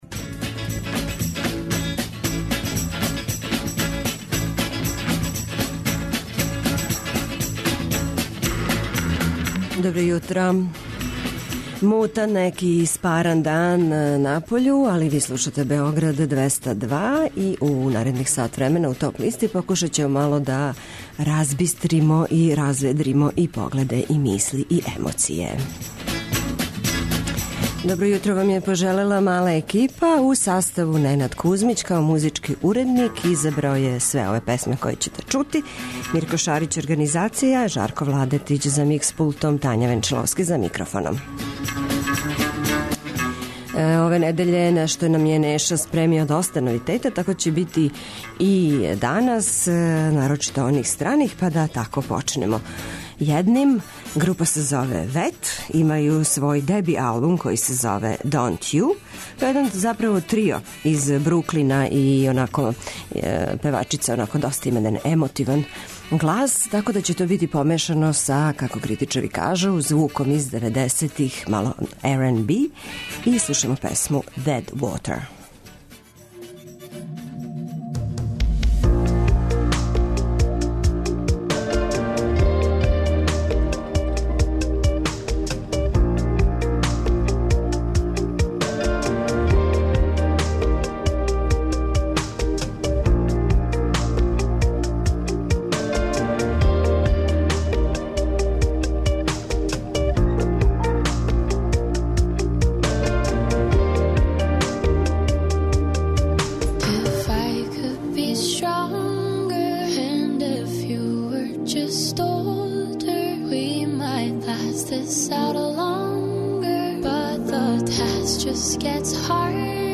преузми : 24.55 MB Топ листа Autor: Београд 202 Емисија садржи више различитих жанровских подлиста.